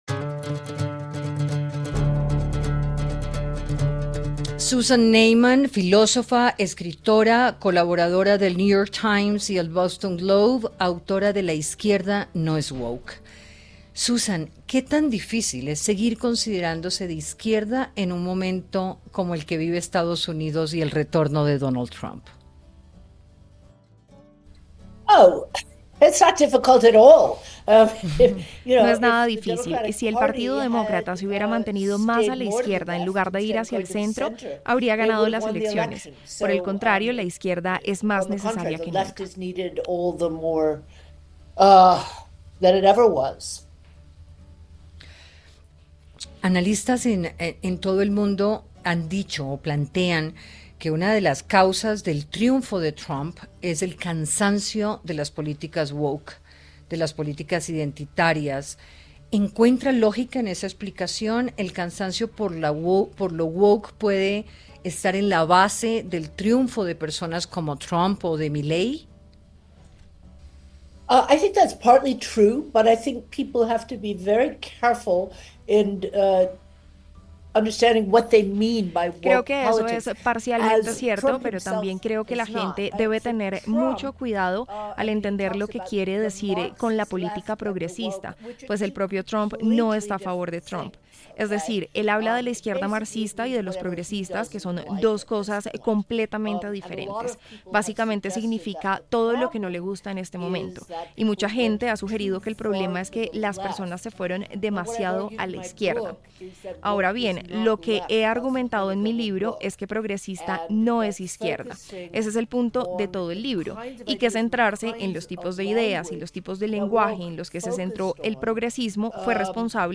La autora de La izquierda no es woke habló con Hora20 en el marco del Hay Festival de la izquierda, el momento político que vive Estados Unidos y el peso de las ideas woke en la sociedad contemporánea